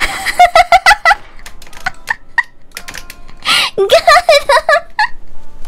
Laugh.wav